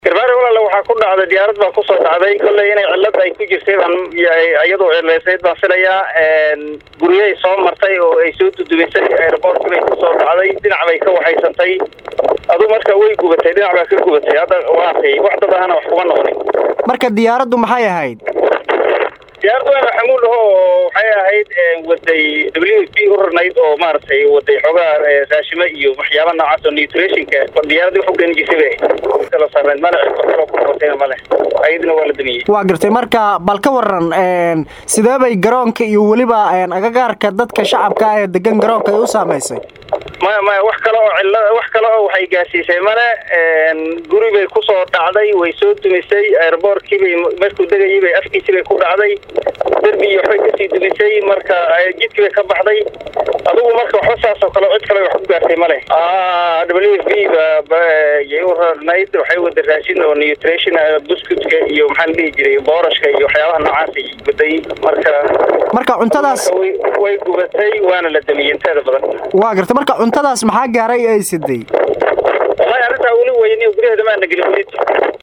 Wareysi-Gudoomiye-ku-Xigeenka-Garbahareey.mp3